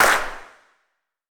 TC3Clap4.wav